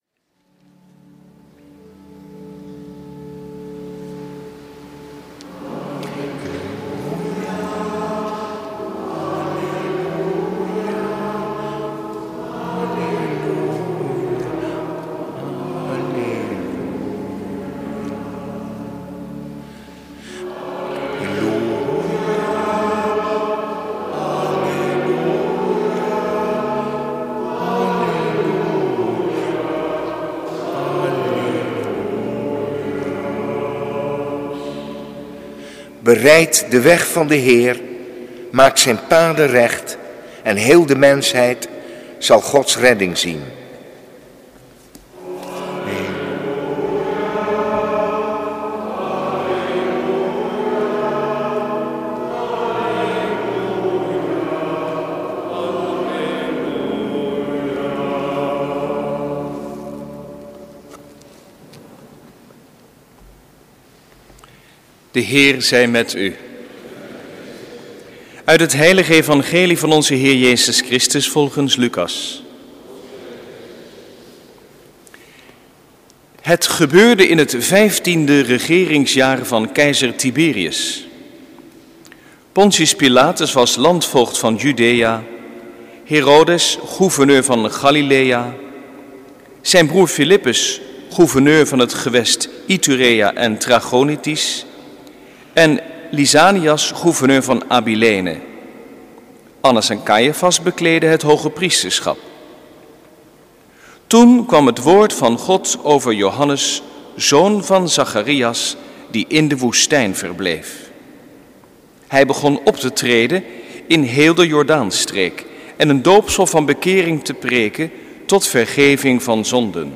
Preek 2e zondag van de Advent, jaar C, 8/9 december 2012 | Hagenpreken
Eucharistieviering beluisteren vanuit de Willibrorduskerk te Wassenaar (MP3)